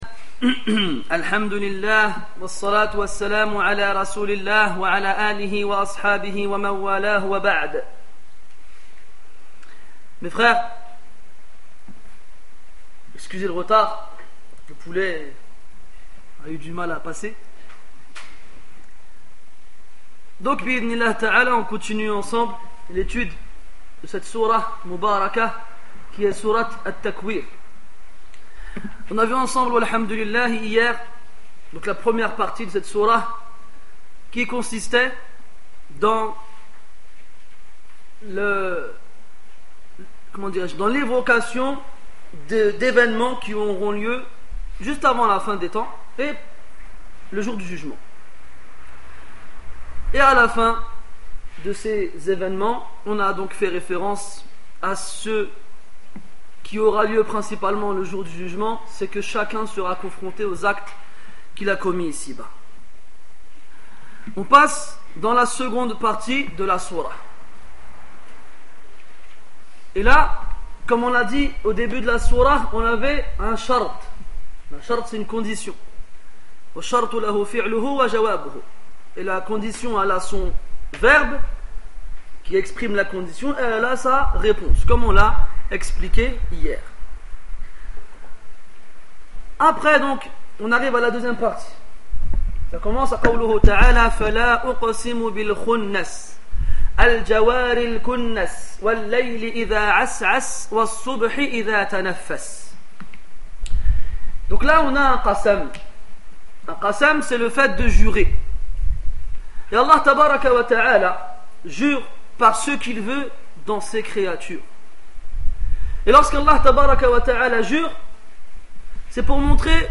Ces deux conférences expliquent une des premières sourates révélées du Coran.